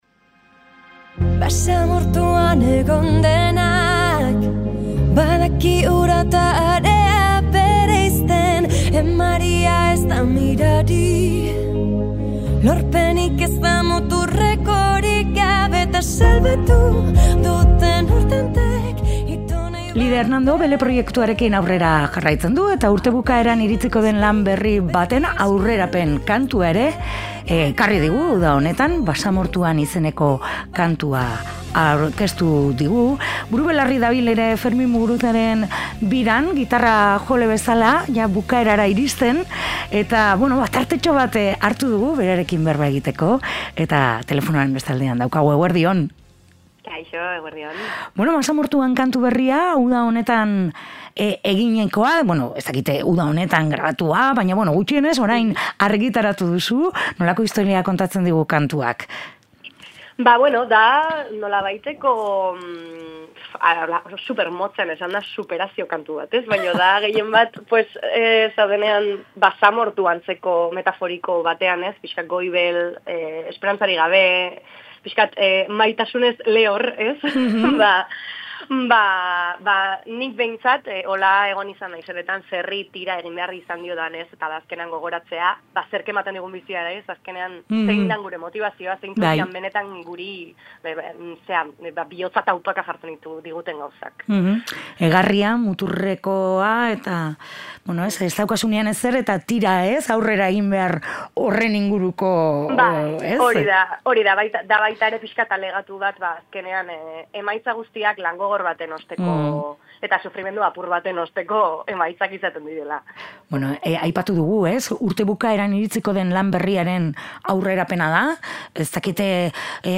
solasean